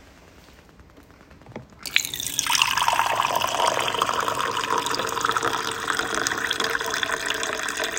main Liquid-Dispensing